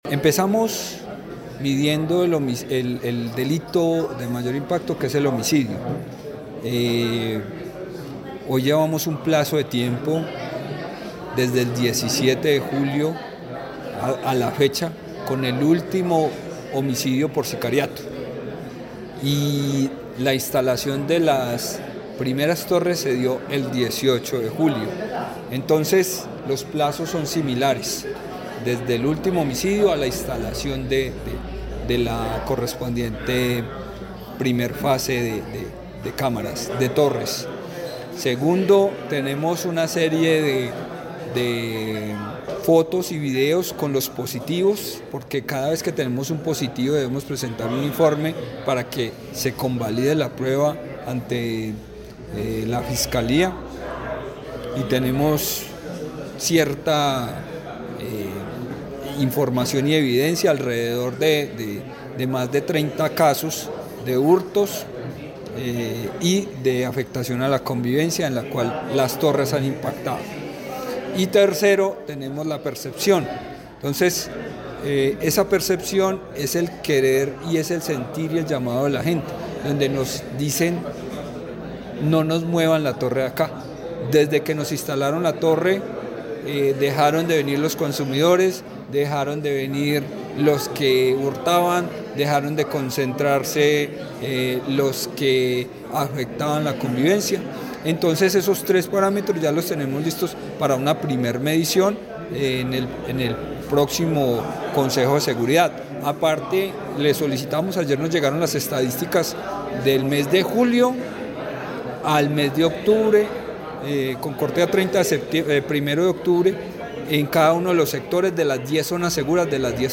Secretario de Gobierno de Montenegro